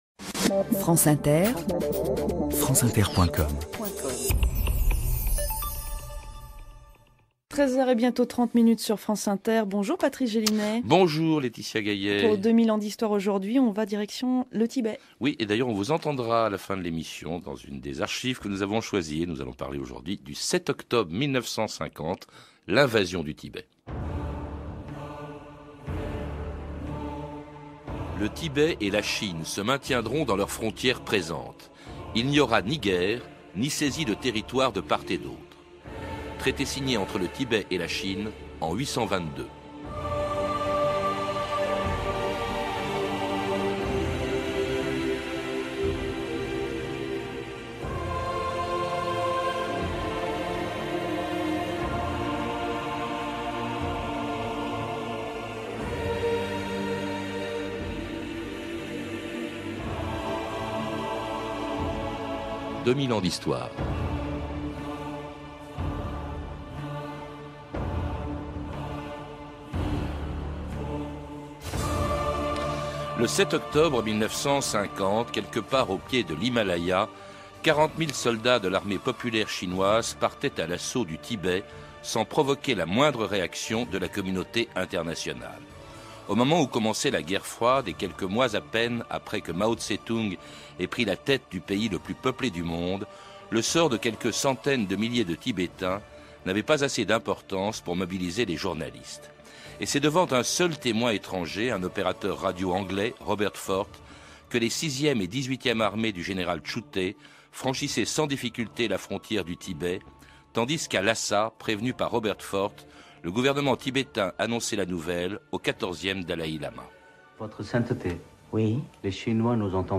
Podcast tiré de l’émission « 2000 ans d’Histoire » sur France Inter, animée par Patrice Gélinet.